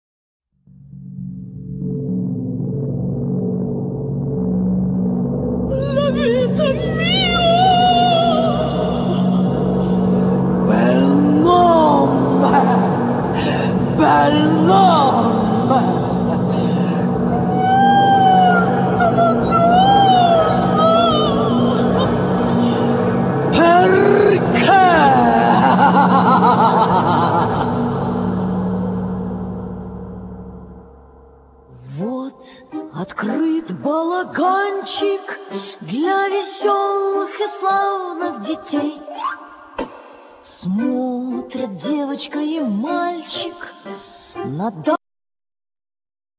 Vocals
Piano,Keyboards,Vocals
Guitar,Percussions
Cello
Flute